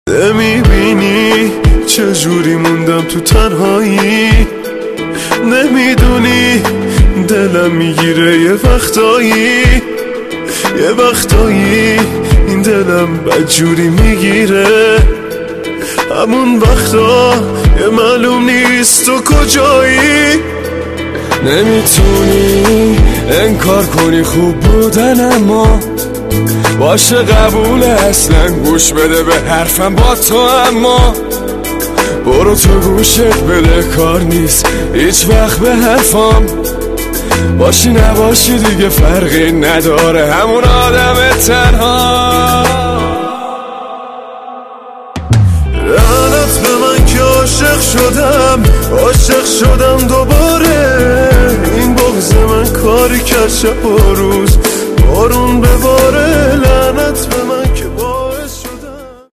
پاپ عاشقانه غمگین